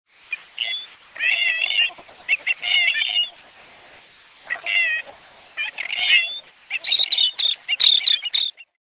Sonidos de piar de pájaros endémicas de los salares de Chile.